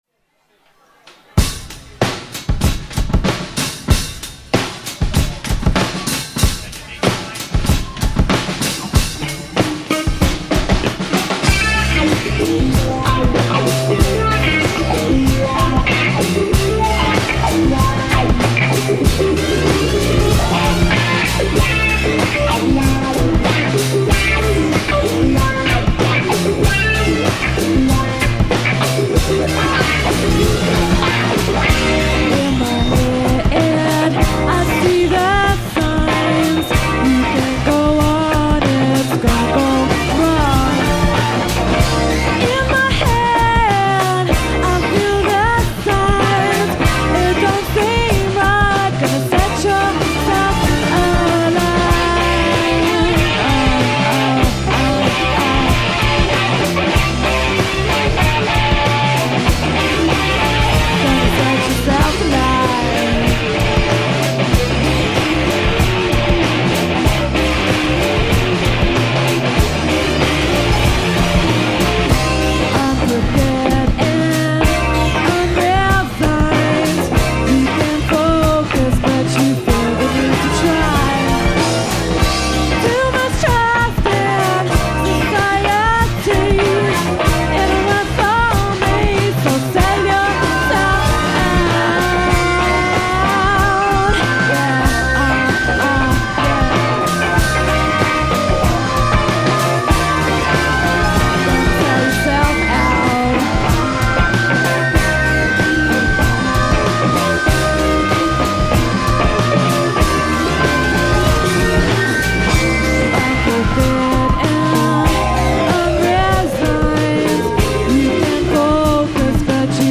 labrador_live.mp3